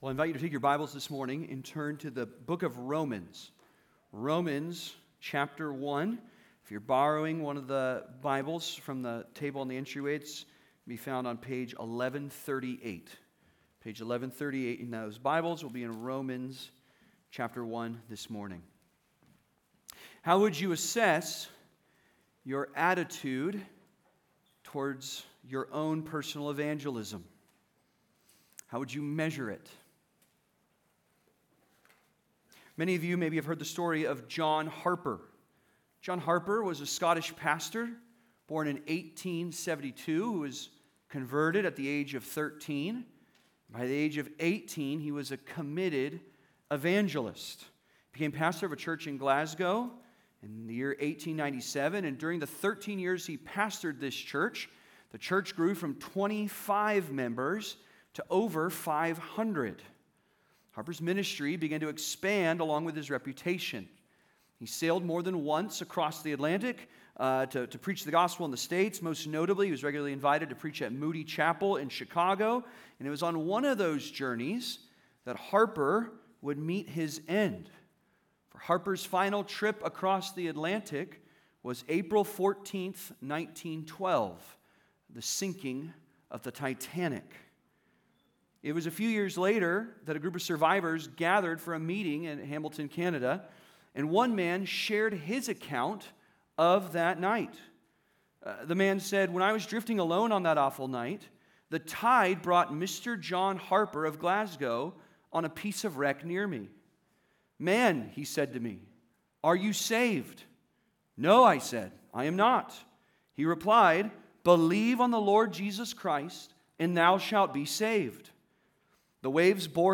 The Hopeful Evangelist (Sermon) - Compass Bible Church Long Beach